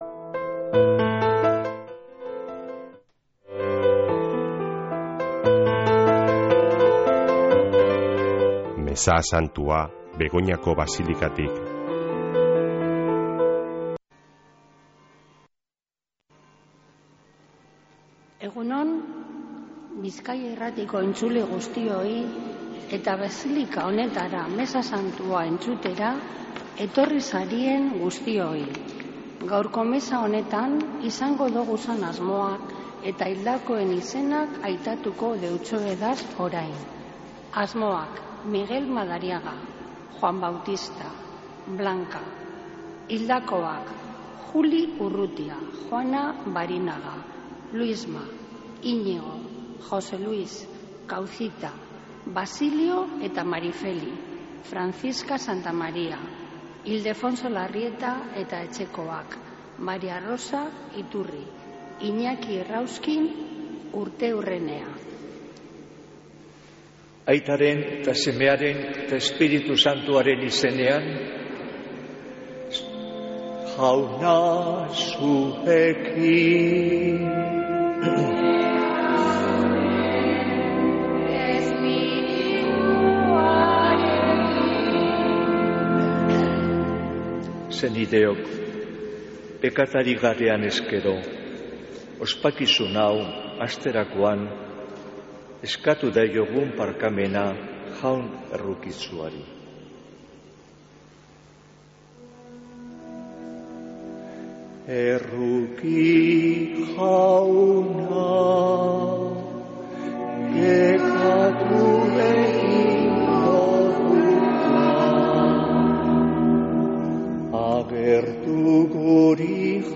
Mezea (25-03-10)